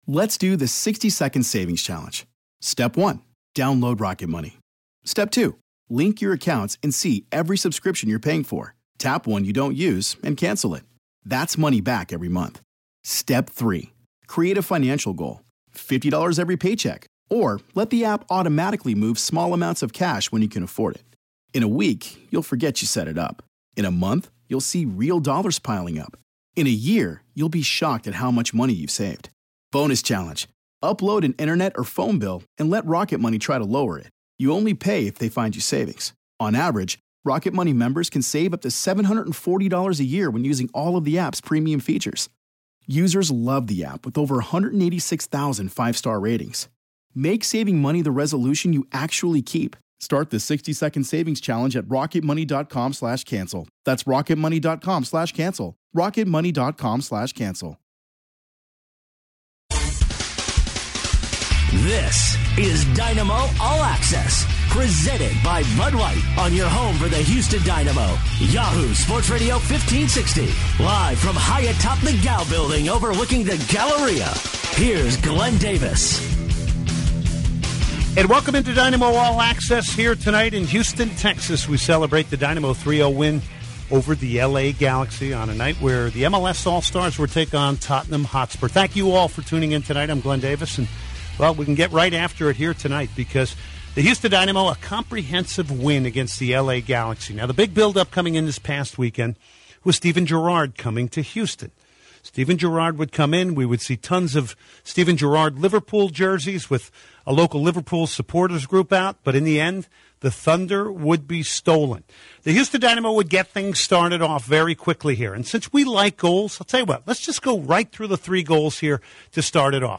He also discusses Cubo Torres skills and what he brings to the Dynamo, and features an interview with him about his transition to Houston.
He also interviews Ownen Coyle, manager of the Dynamo to discuss the win over the Galaxy and player rotation.